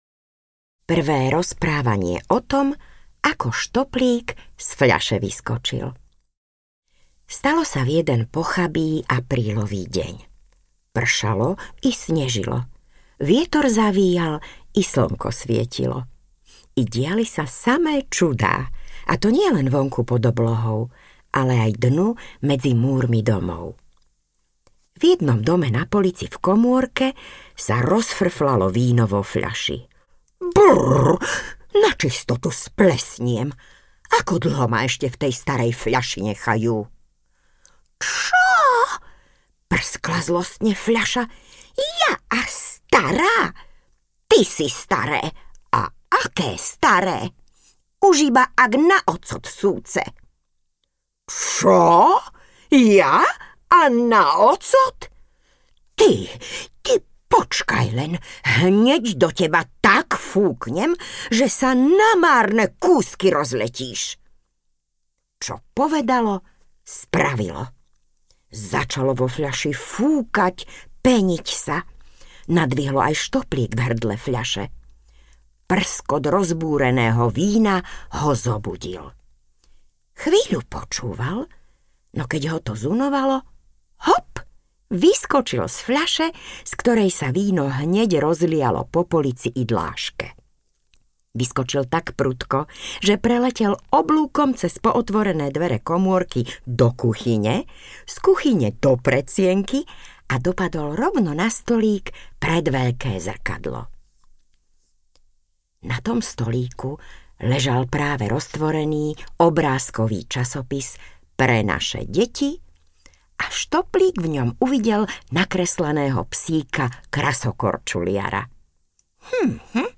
O Štoplíkovi, kým do školy nechodil audiokniha
Ukázka z knihy